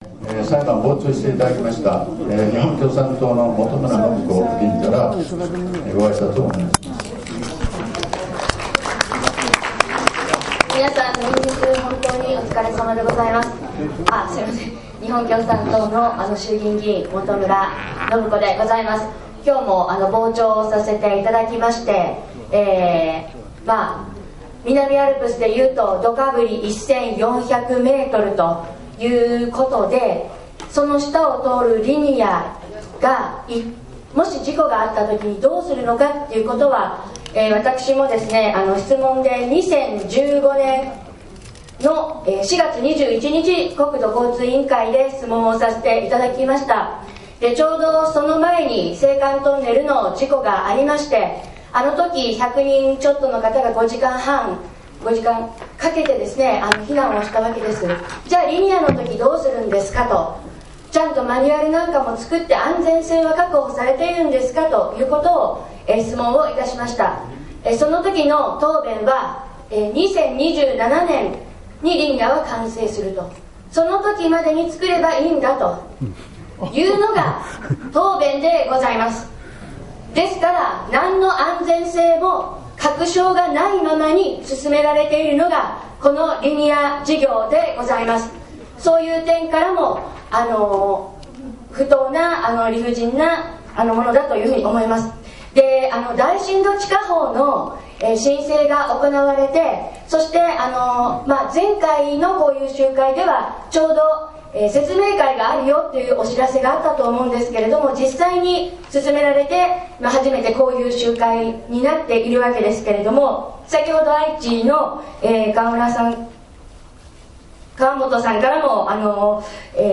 冒頭部分で日本共産党の本村伸子衆議院議員のあいさつがありました。シンポジウムの録音です。
シンポジウムの最後に原告団事務局からの報告がありました。